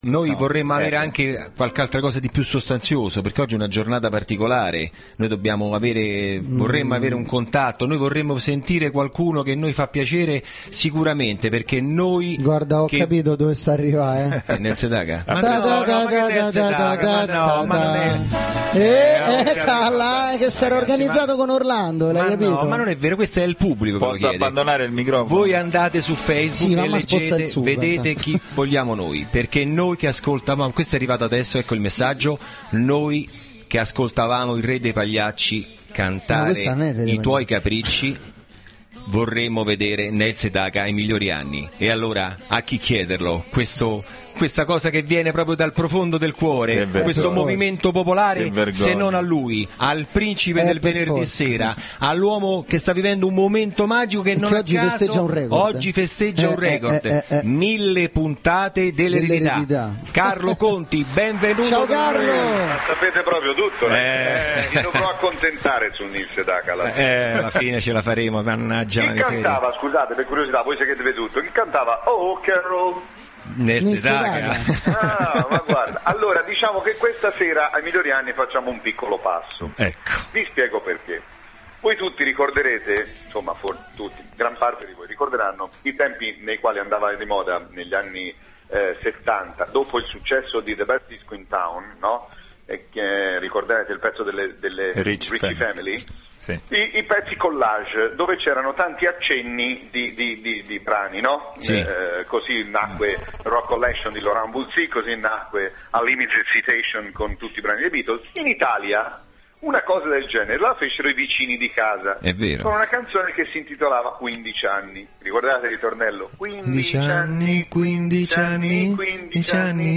Intervento telefonico Carlo Conti del 19/11/2010